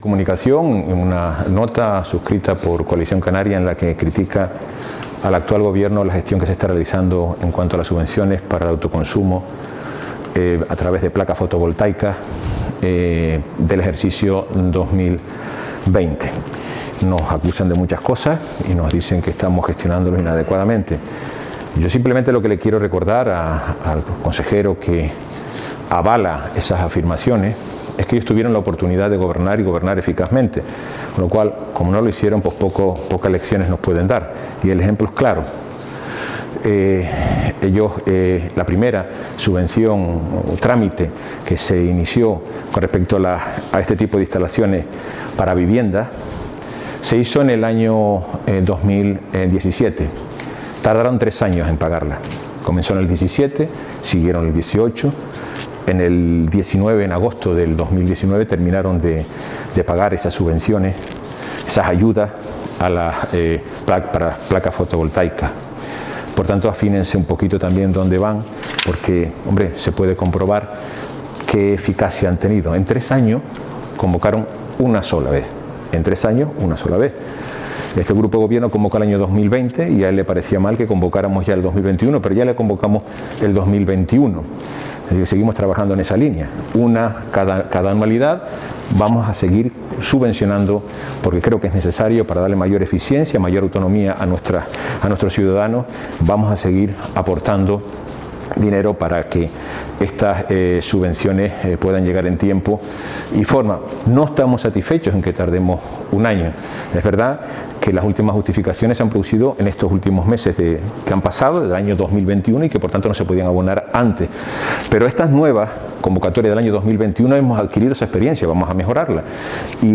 Carlos Cabrera Declaraciones Subvenciones Energía (1).mp3